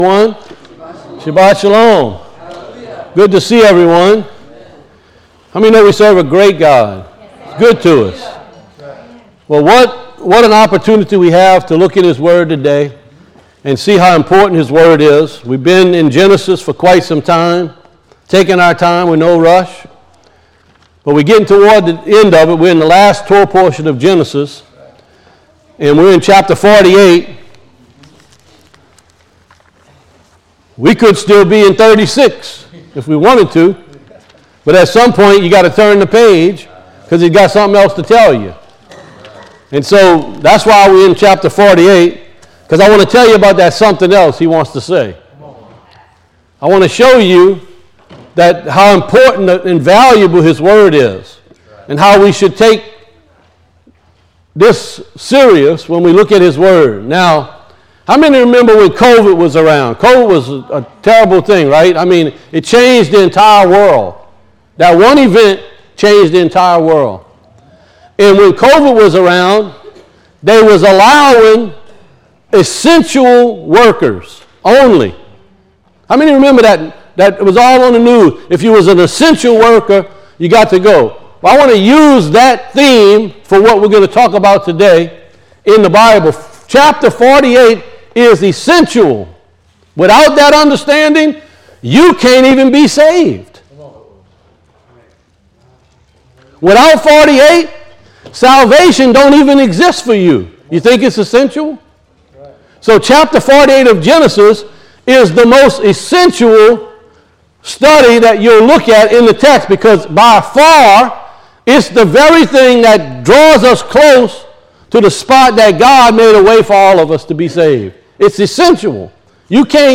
Service Recordings